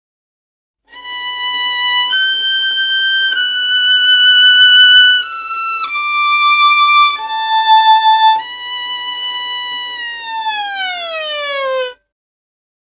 Saddest_Violin_9_8va_glis_end
cinema famous film funny hearts-and-flowers motif movie sad sound effect free sound royalty free Movies & TV